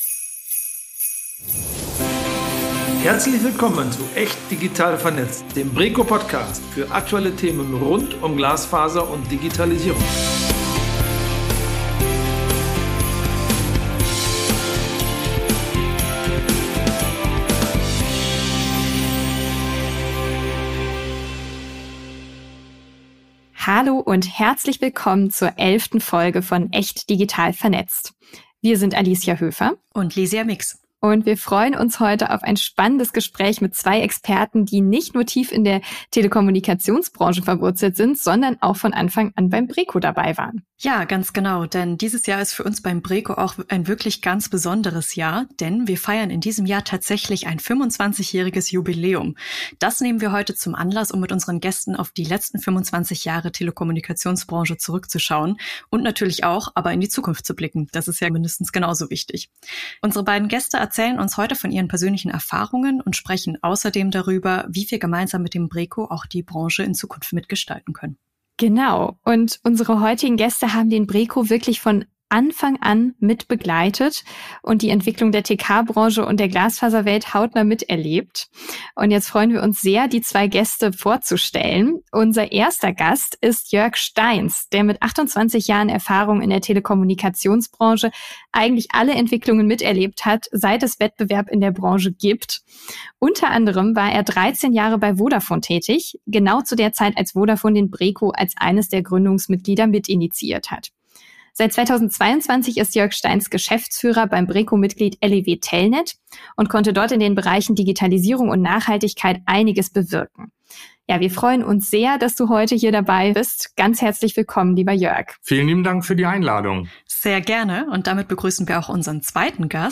In Form von echten Gesprächen mit Expert:innen werden Themen und aktuelle Trends mit Bezug zur digitalen Infrastruktur als Fundament für eine digitale Transformation der Wirtschaft und Gesellschaft beleuchtet.